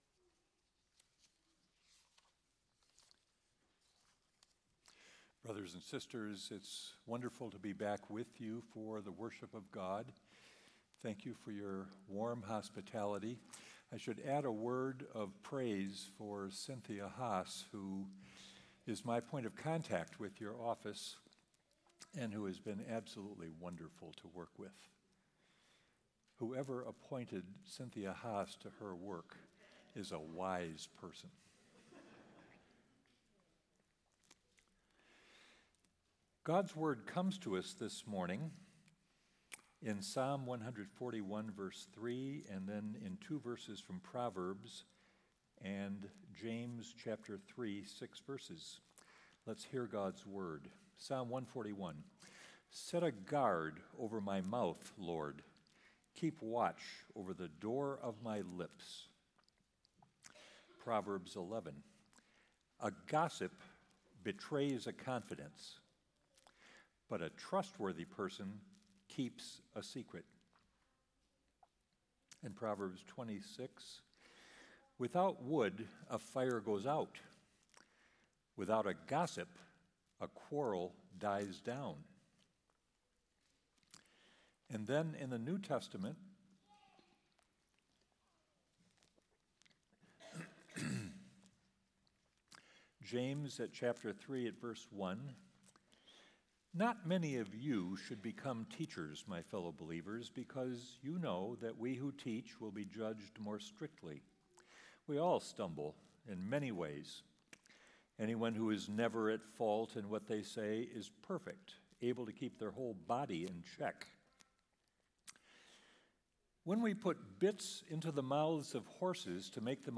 Sermon Recordings | Faith Community Christian Reformed Church